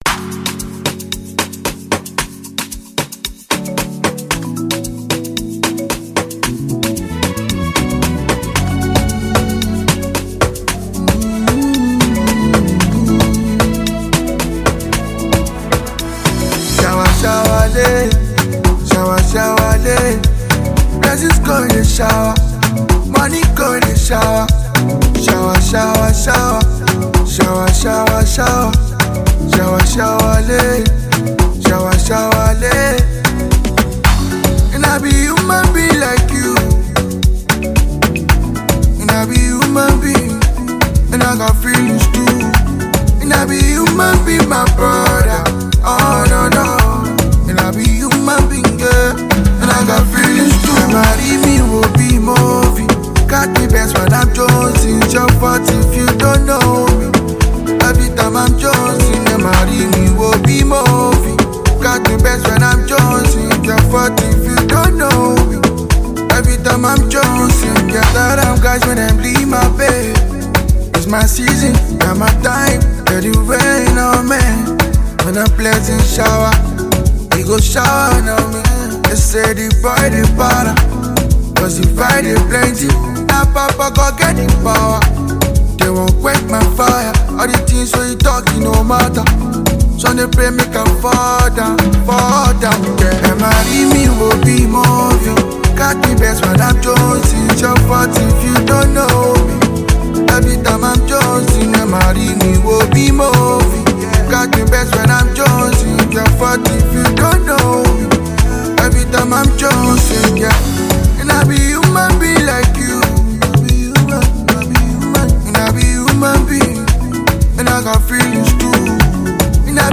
New Afro-fusion with some amapiano groove